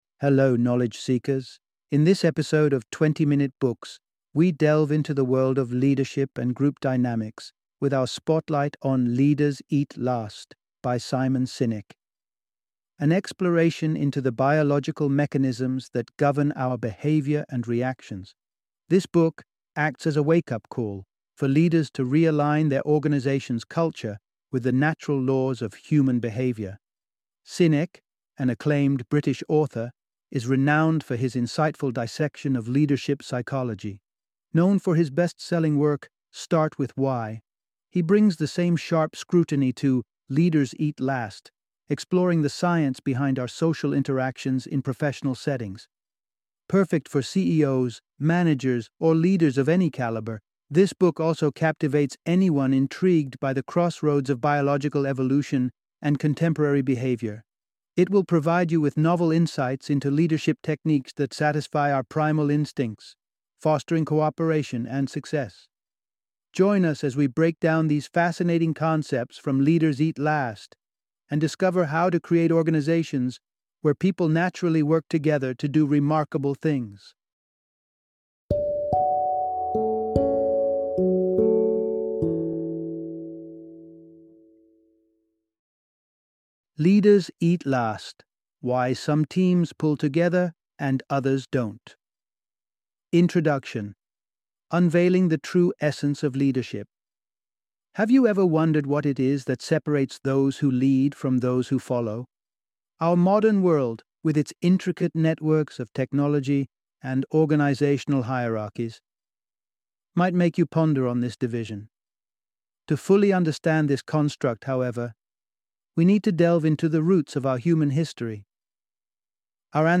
Leaders Eat Last - Audiobook Summary